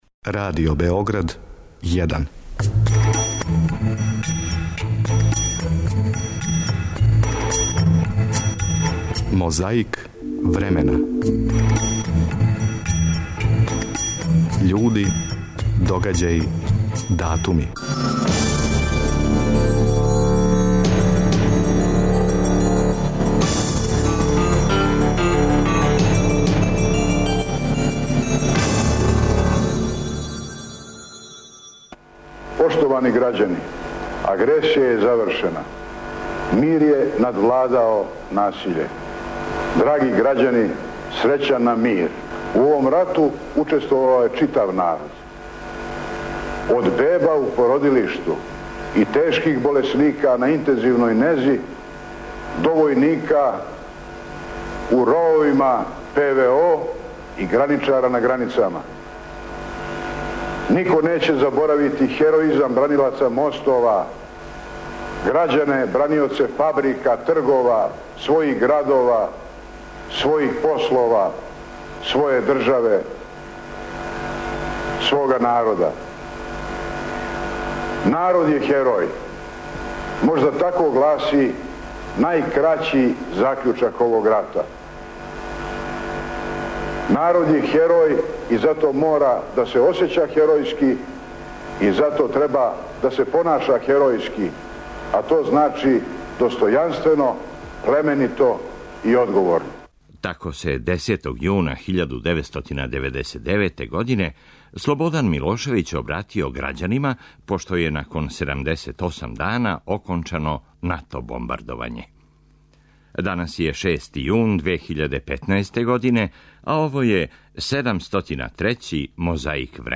Сећамо се, како се, у 10. јуна 1999. године обратио грађанима, пошто је након 78 дана окончано НАТО бомбардовање.
9. јуна 1991. године, на београдском Тргу републике одржан је митинг удружене демократске опозиције.